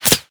bullet